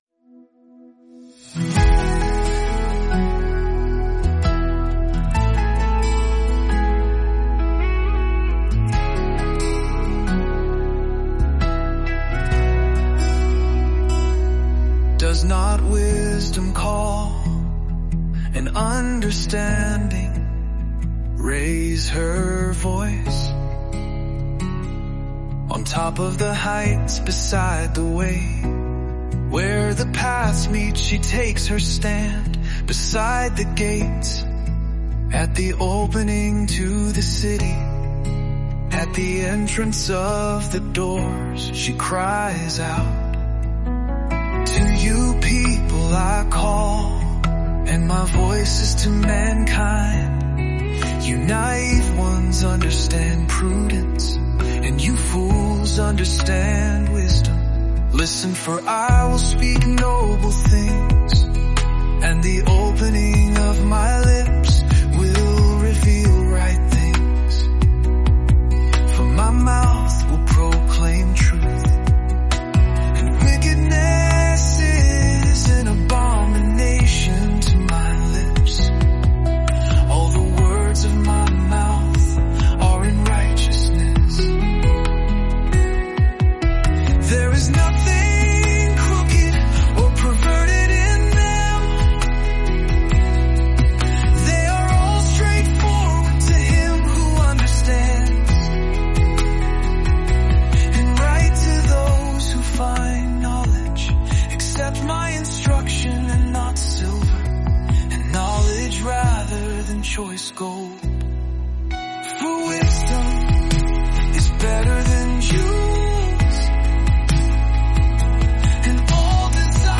Immerse yourself in the timeless wisdom of Proverbs in just 31 days through word-for-word Scripture songs. Each day, listen to passages that offer guidance for daily life—covering wisdom, integrity, relationships, work, and the fear of the Lord—brought to life through music.